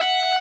guitar_001.ogg